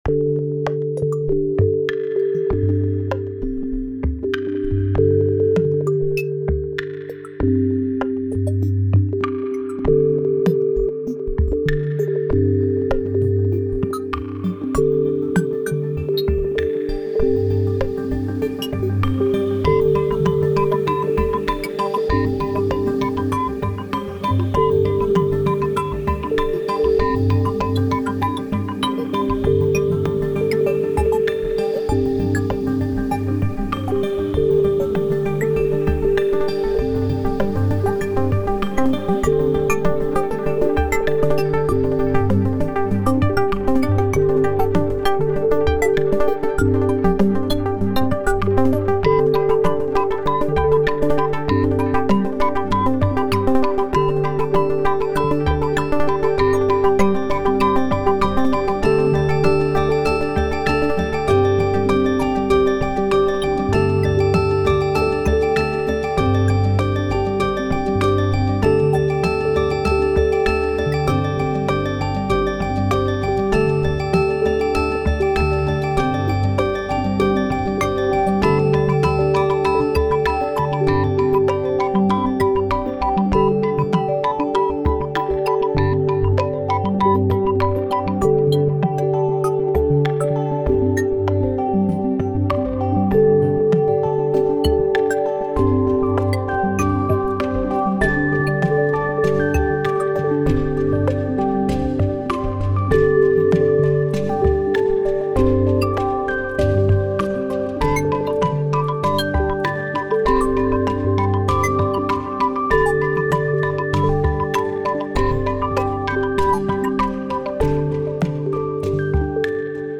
タグ: Chill フィールド楽曲 幻想的 海/水辺 電子音楽 コメント: 仮想空間の水中をイメージしたフィールド楽曲。